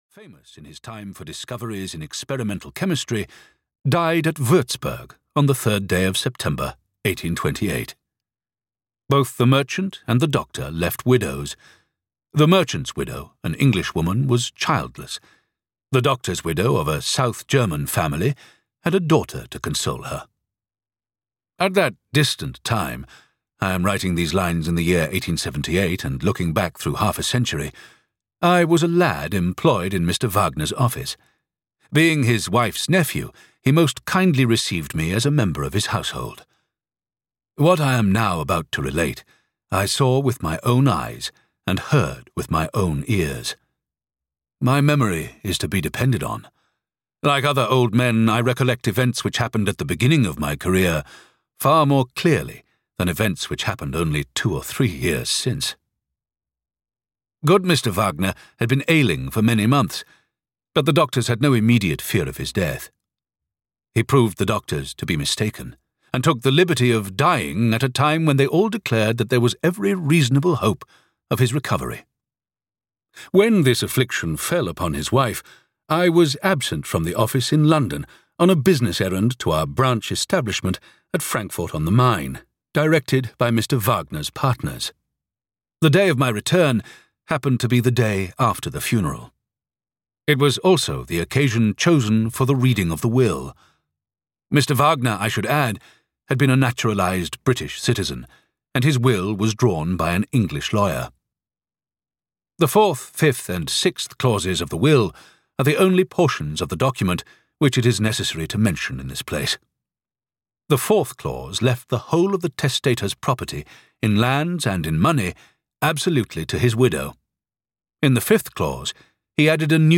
Jezebel’s Daughter (EN) audiokniha
Audiobook Jezebel’s Daughter, written by Wilkie Collins.
Ukázka z knihy